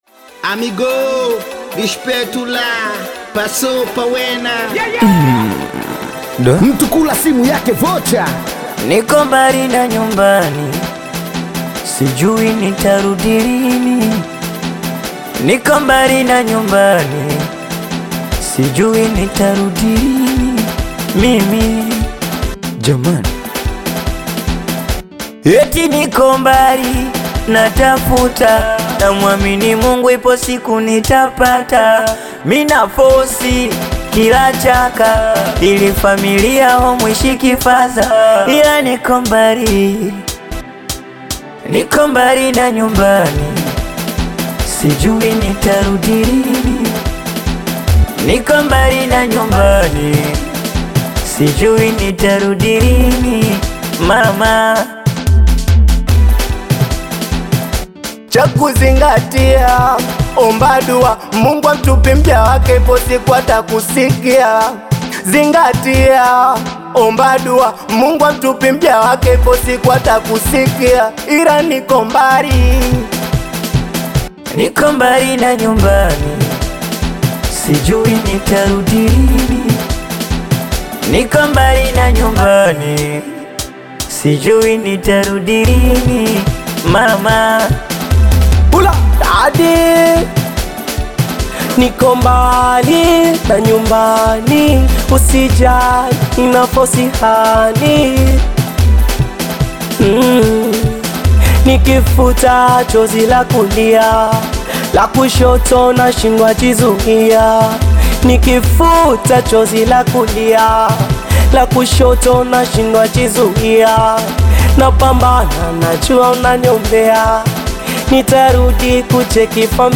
energetic Singeli single
Genre: Singeli